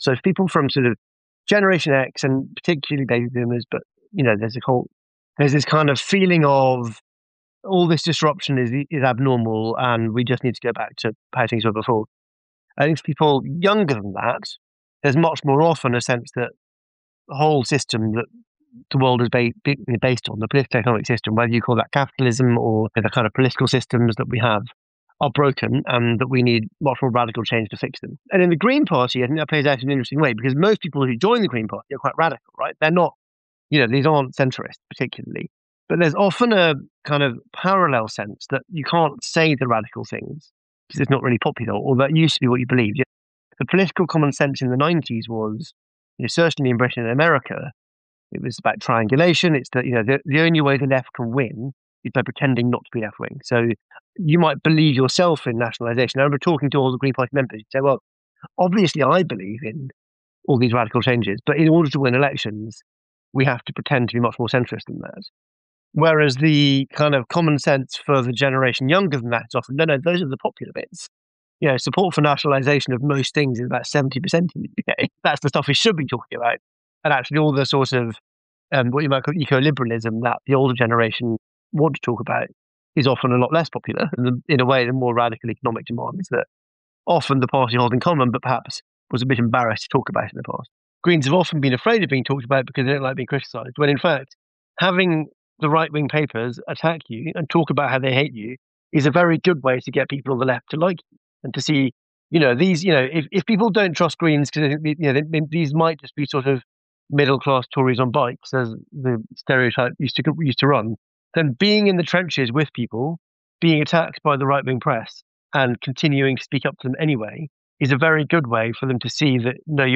Die beiden erzählen im WAS TUN-Gespräch, was seit September 2021 passiert ist, was genau in dem Gesetz steht und wie die Vergesellschaftung für den Landeshaushalt sogar ohne zusätzliche Kosten gelingen kann – aber am Wichtigsten: Wie ihr mitmachen könnt.